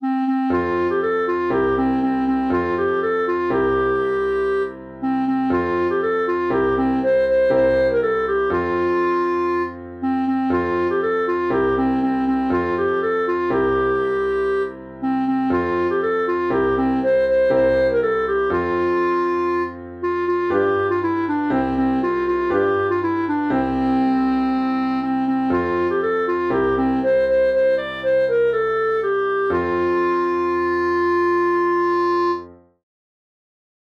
Tot cantant i amb alegria (Cançó tradicional catalana)
Interpretació musical de la canço tradicional catalana